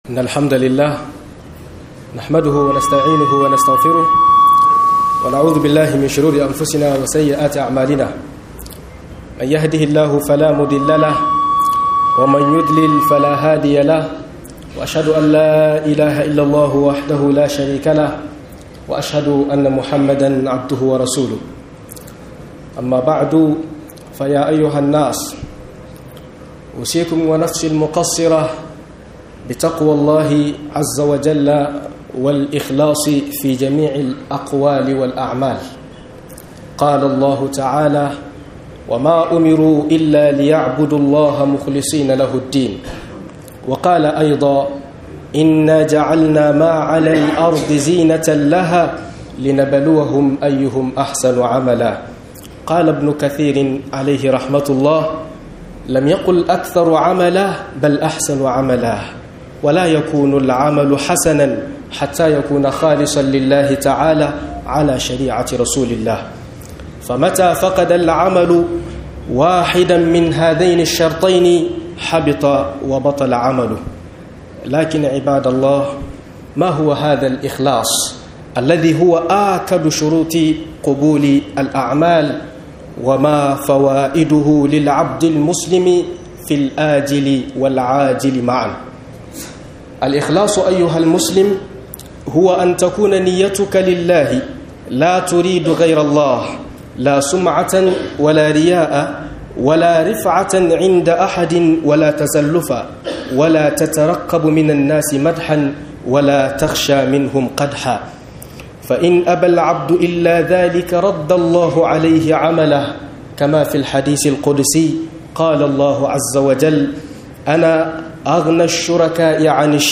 Yi dan allah da fa'idodin sa - MUHADARA